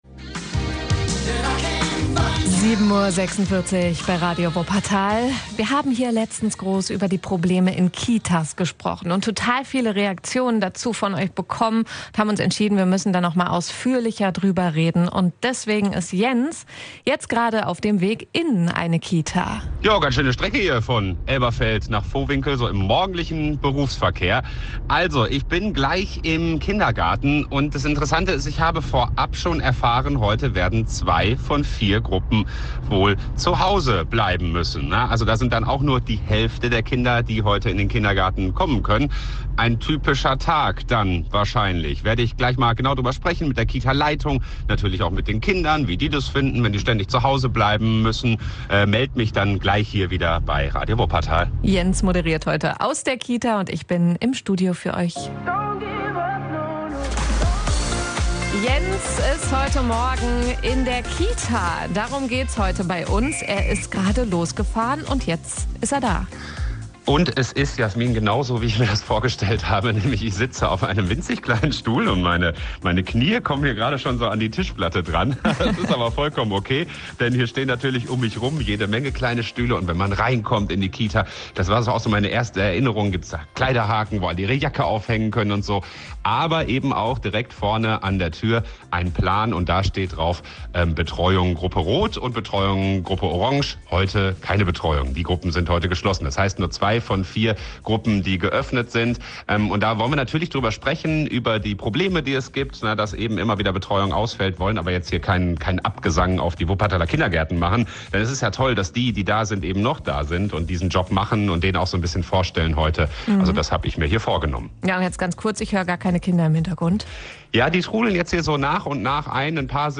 _-_kita_1_erster_eindruck_und_interview_mit_kita-leitung.mp3